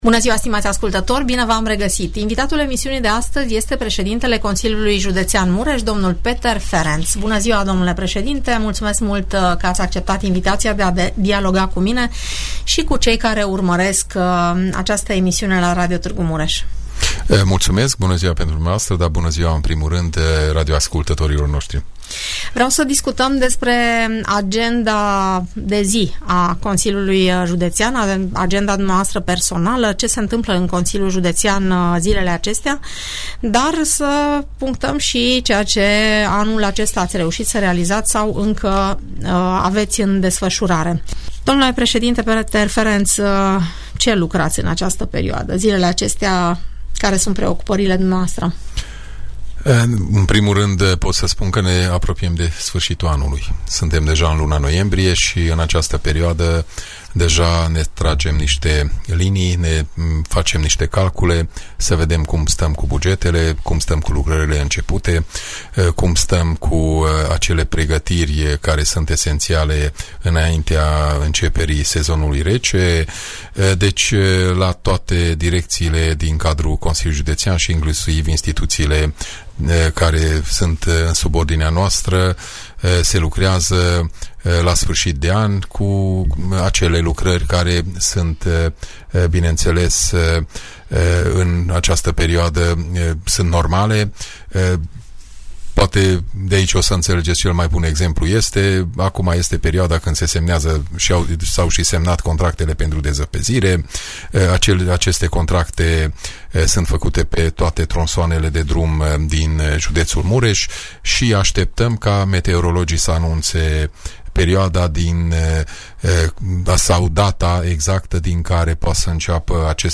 Peter Ferenc, președintele Consiliului Județean Mureș, vorbește în emisiunea "Părerea ta", despre proiectele în derulare dar și despre cele care urmează să fie finalizate anul acesta.